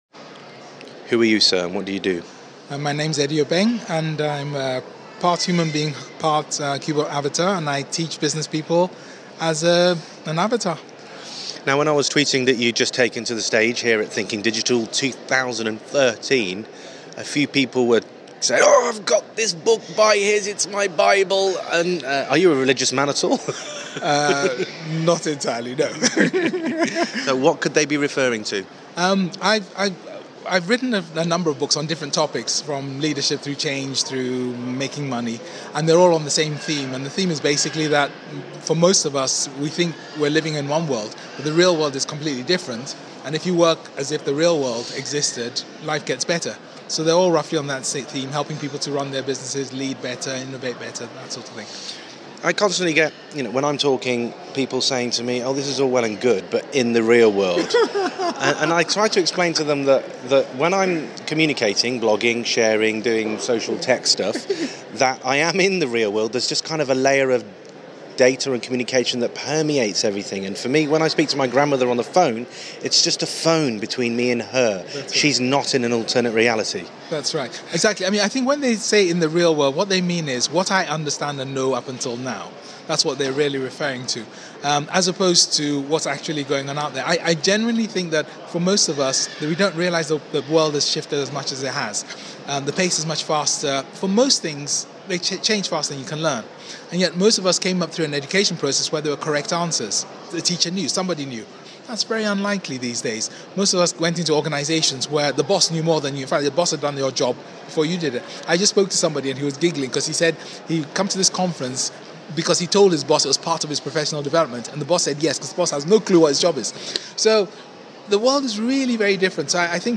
A corridor chat